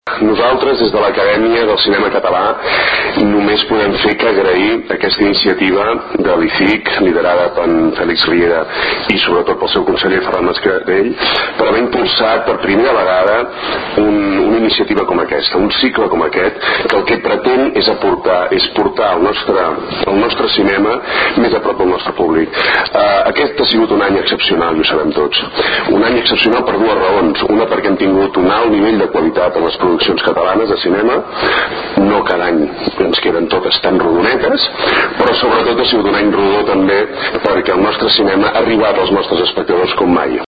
Intervencions durant la roda de premsa